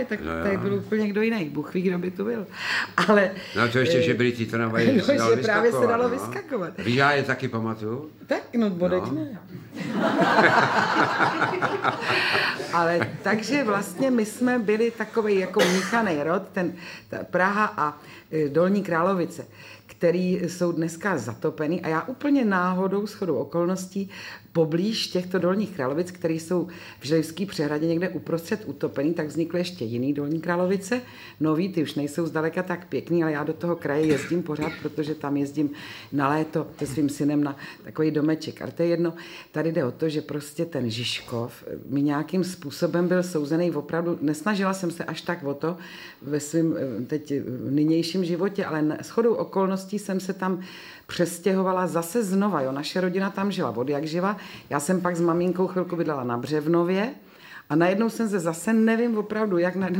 Audiobook
Read: Radoslav Brzobohatý